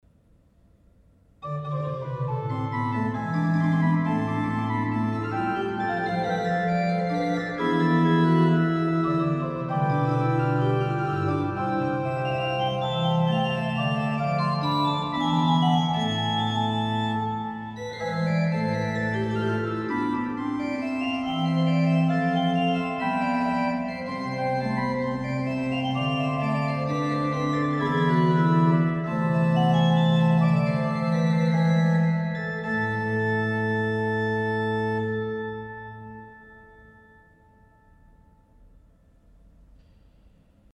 Bourdon 8'
Flûte conique 4'
Tremblant doux.
Vous trouverez un très bel orgue-positif avec une construction artisanale en chêne massif avec 4 registres.
A' = 440 Hz./18°. Tempérament, inégale, Valotti